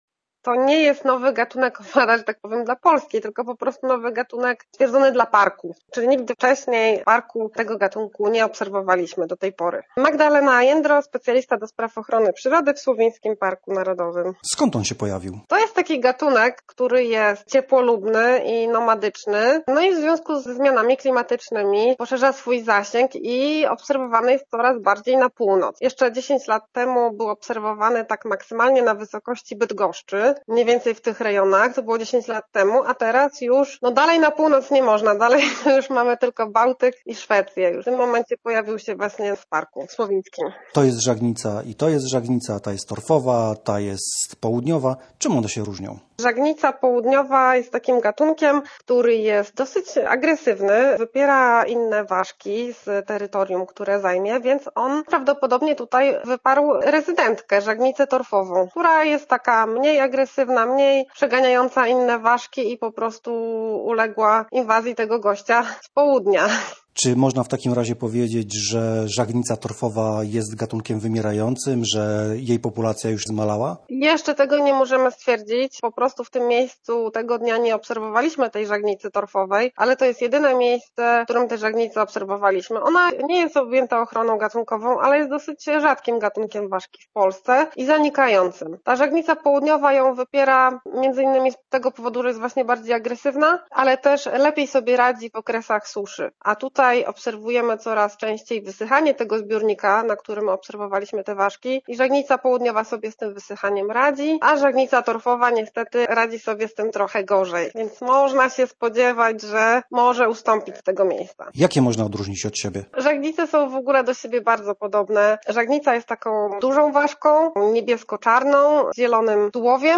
Posłuchaj całej rozmowy naszego reportera